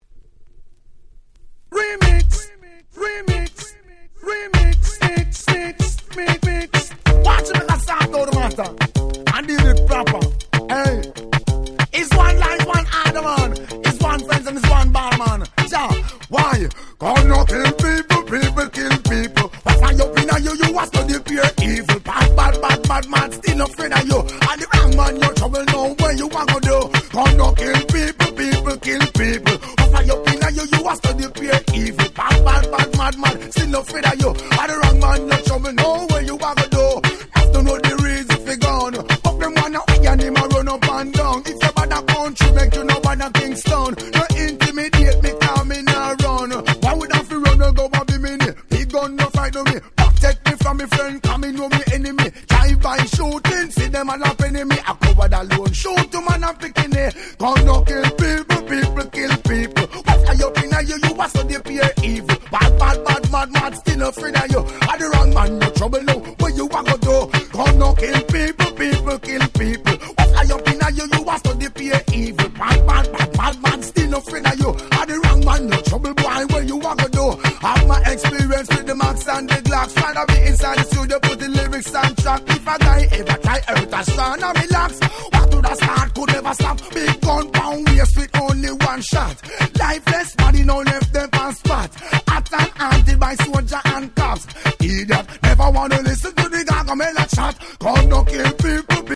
Reggaeの非常に使えるマッシュアップを全5トラック収録した1枚！！